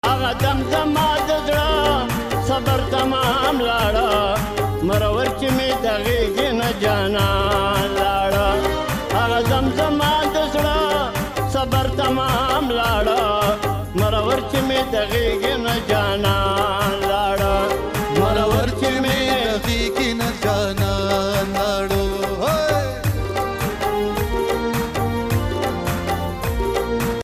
Pashto Song